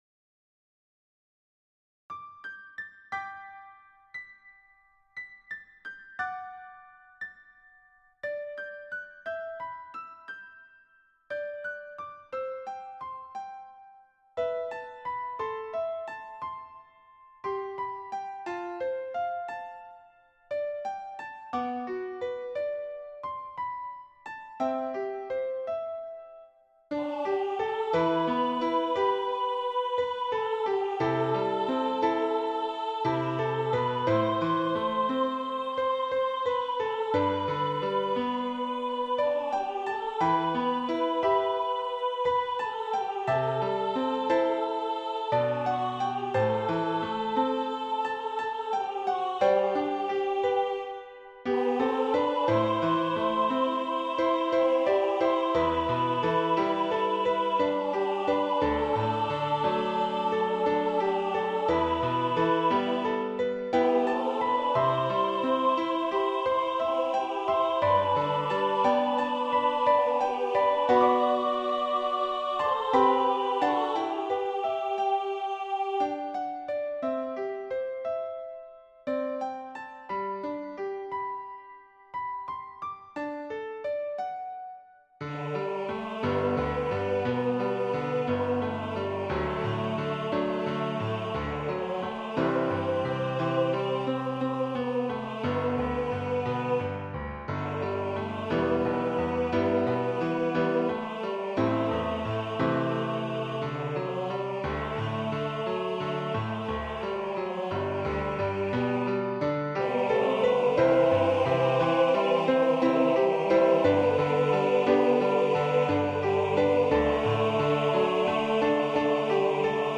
Voicing/Instrumentation: SATB We also have other 41 arrangements of " O My Father ".
Choir with Soloist or Optional Soloist Piano